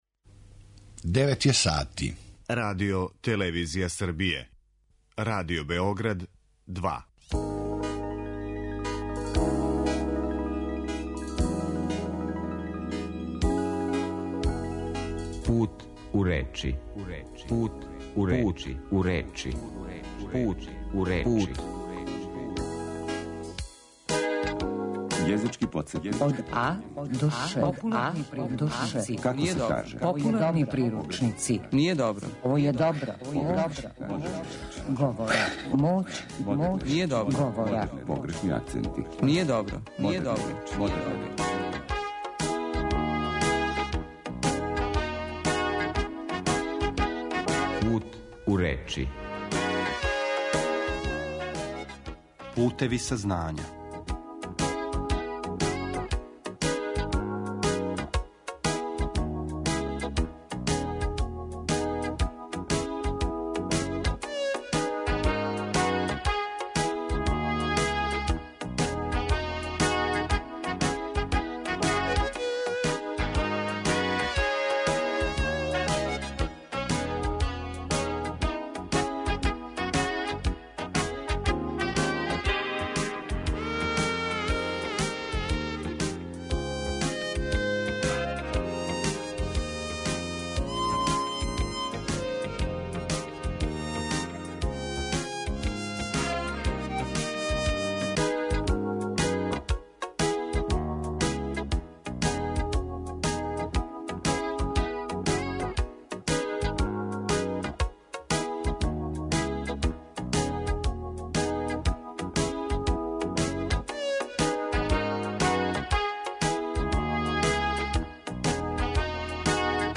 Емисија о лингвистици, нашем књижевном језику у теорији и пракси, свакодневној вербалној комуникацији и говору на медијима.